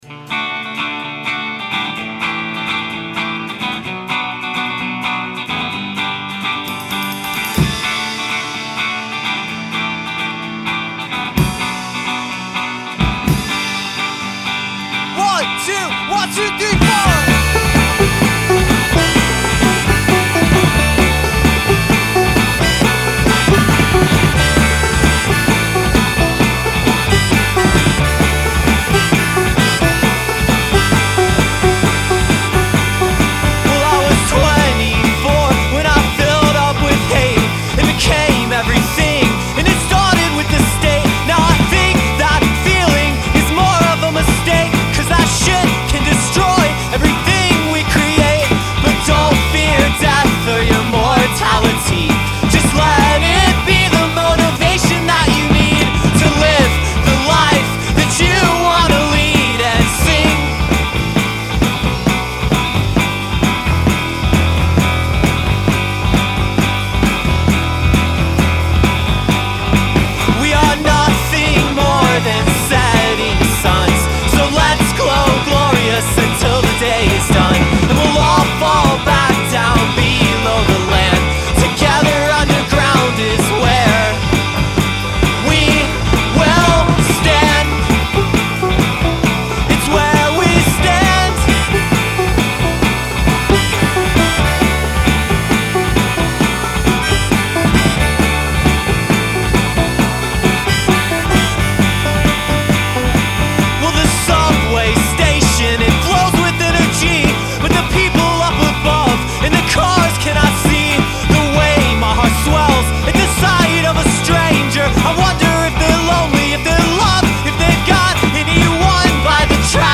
guitar, harmonica, and vocals
banjo and more
synth and vocals
lead guitar